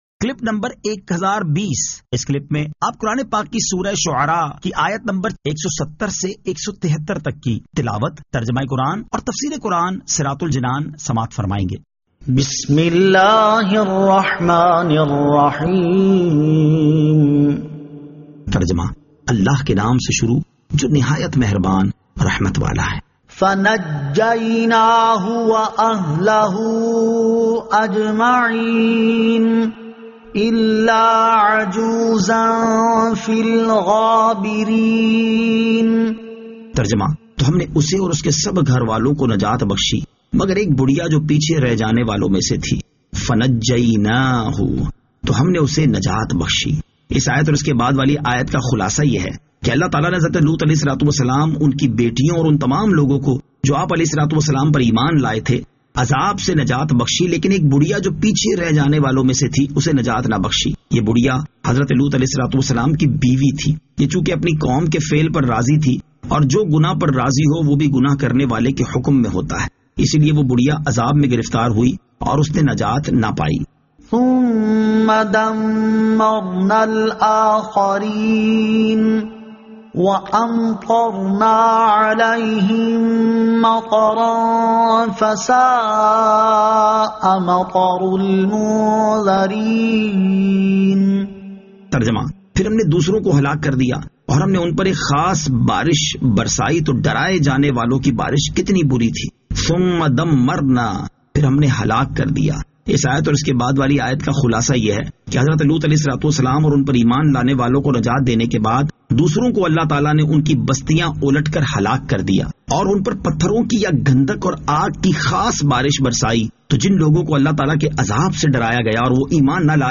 Surah Ash-Shu'ara 170 To 173 Tilawat , Tarjama , Tafseer